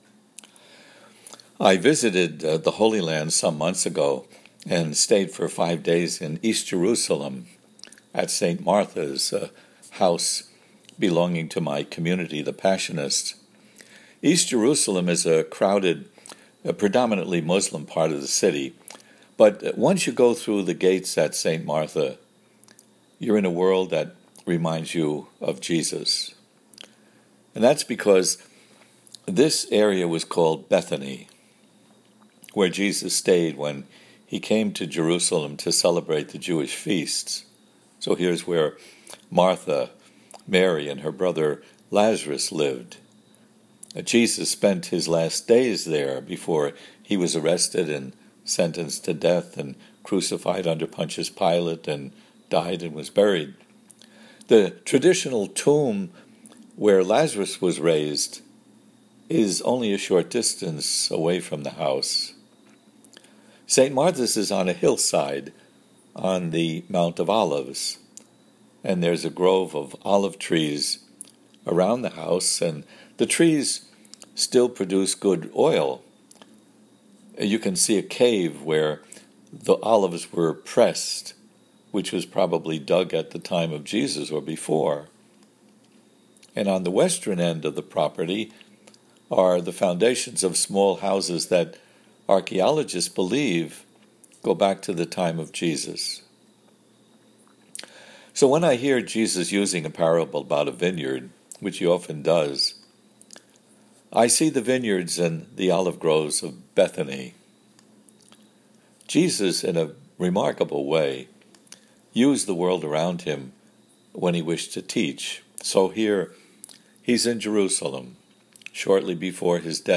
For audio version of the sermon, see below: